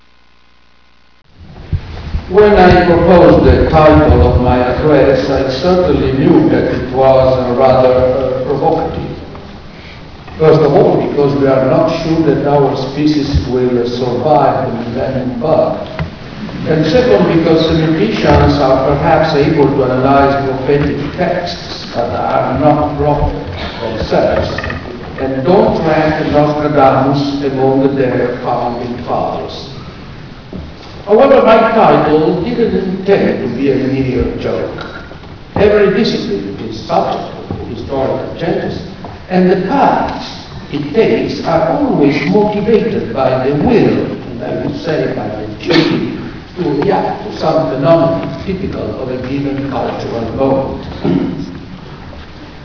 Abertura-Eco-Dresden.wav